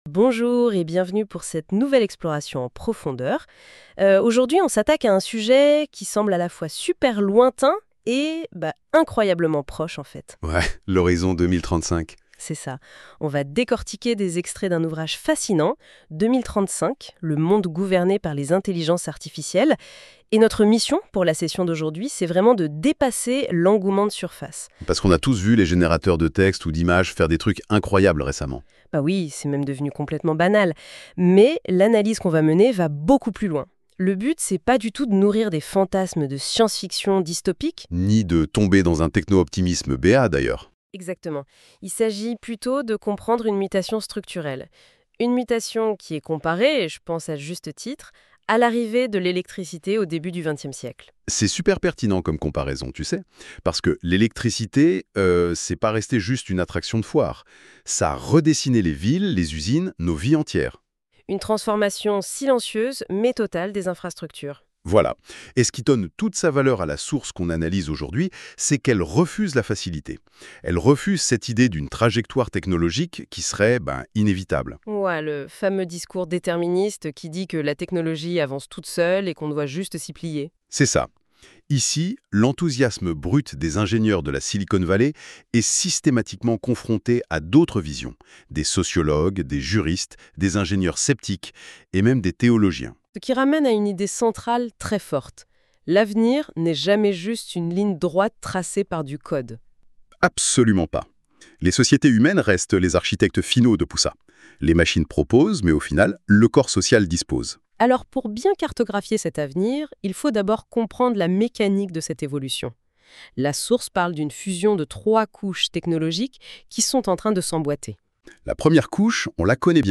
Débat à écouter ici